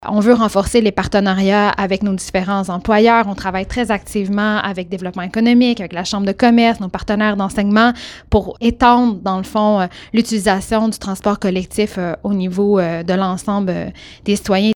En entrevue avec le FM 103,3, celle-ci affirme que les commerces et les maisons d’enseignements font partie d’une vision à long terme.